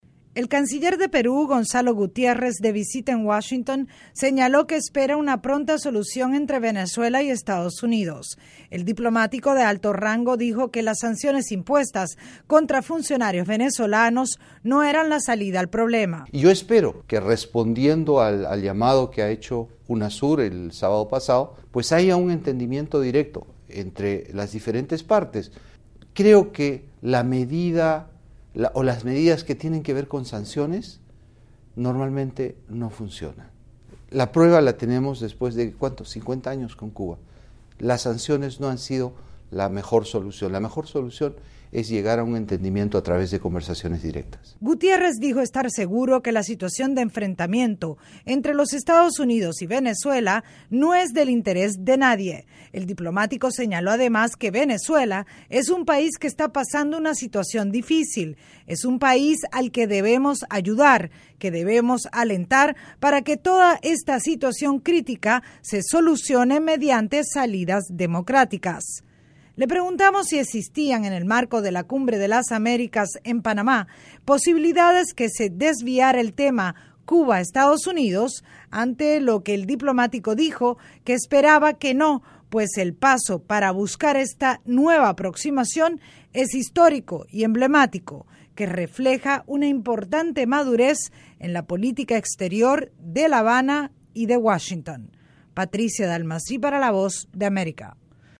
El canciller de Perú conversa con la Voz de América sobre las relaciones entre Estados Unidos y Venezuela, y la próxima cumbre en Panamá.